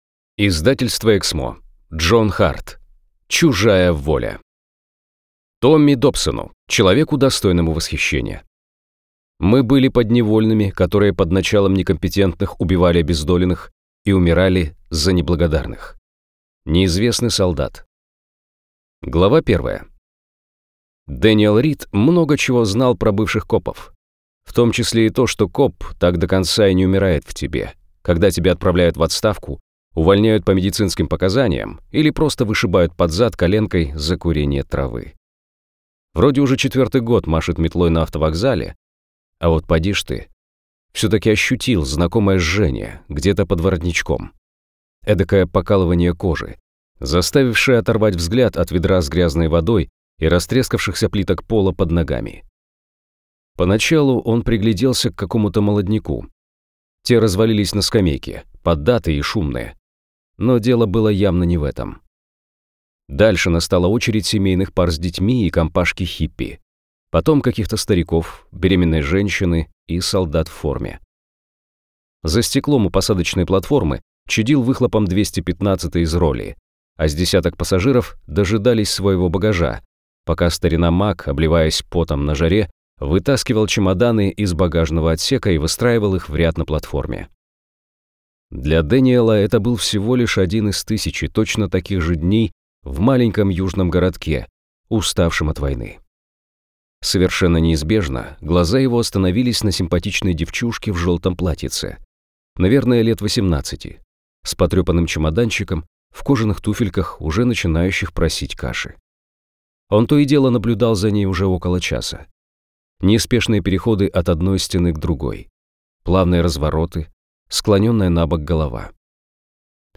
Аудиокнига Чужая воля | Библиотека аудиокниг
Прослушать и бесплатно скачать фрагмент аудиокниги